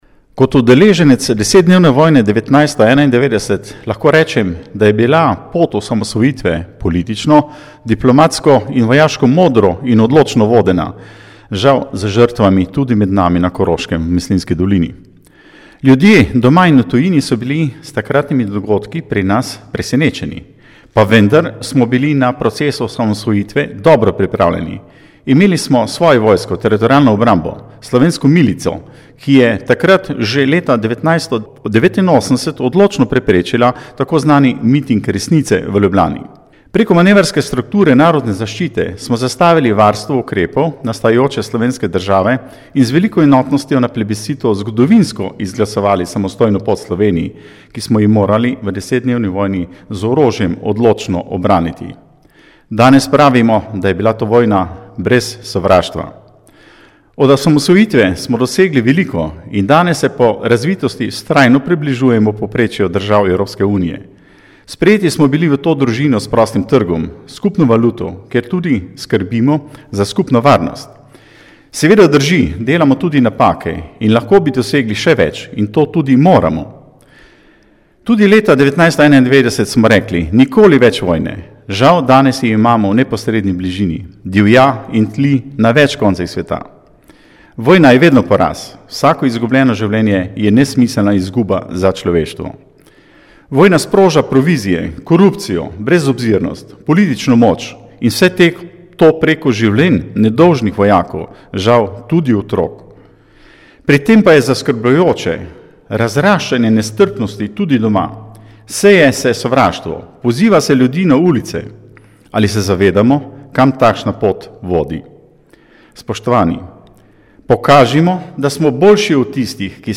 Nagovor ob prazniku dnevu samostojnosti in enotnosti, veterani